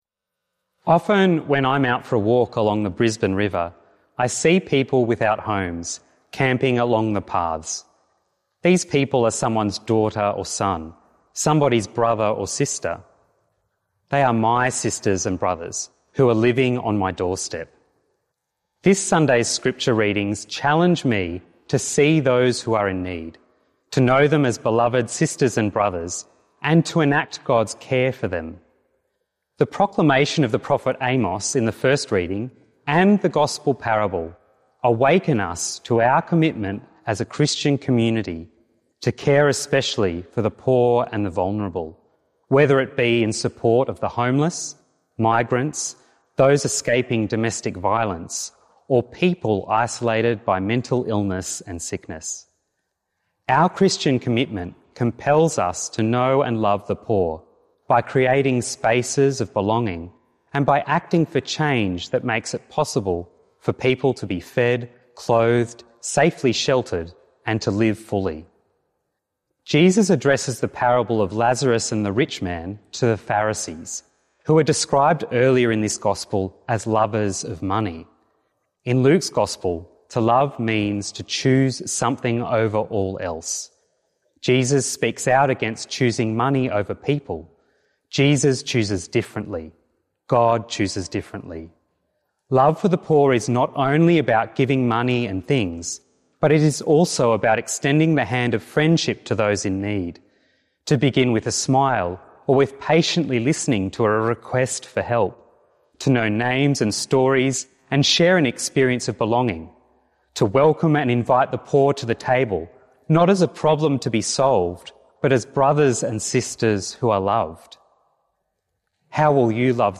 Twenty-Sixth Sunday in Ordinary Time - Two-Minute Homily